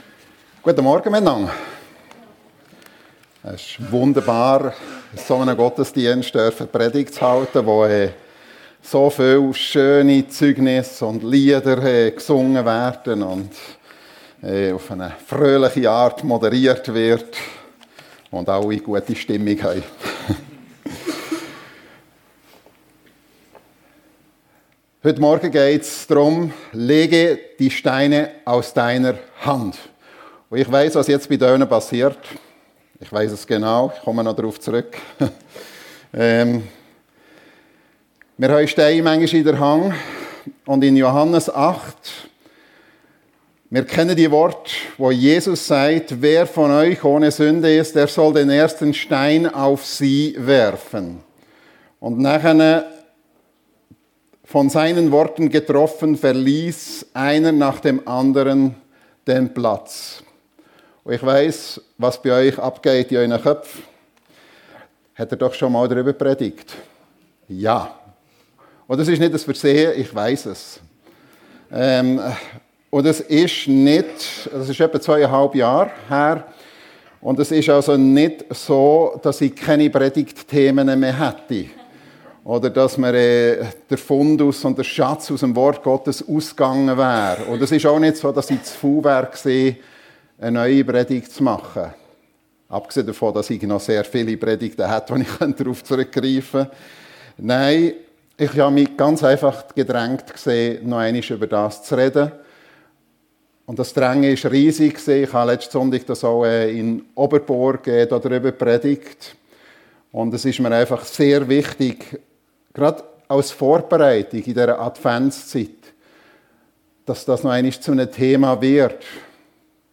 Lege die Steine aus deiner Hand ~ FEG Sumiswald - Predigten Podcast